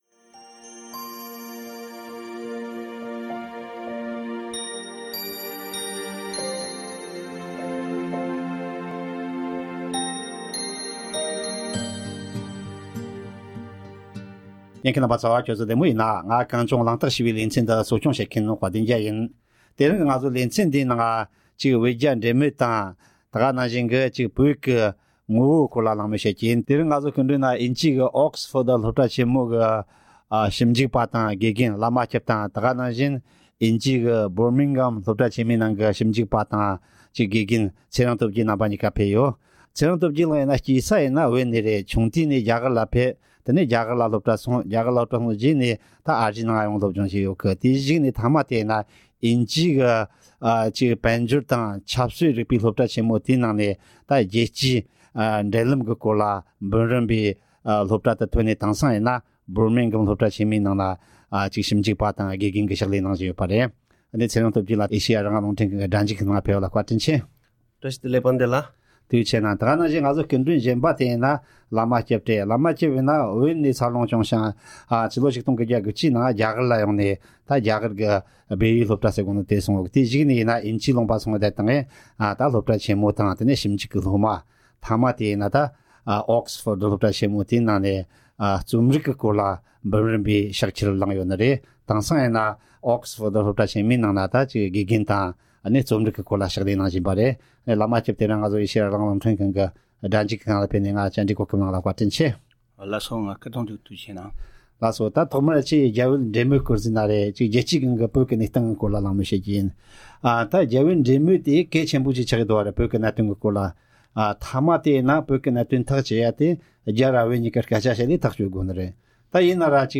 བོད་རྒྱའི་འབྲེལ་མོལ་གྱི་གནས་བབ་དང་བོད་མིའི་ངོ་བོ་སོགས་ཀྱི་ཐད་ལ་གླེང་མོལ་ཞུས་པ།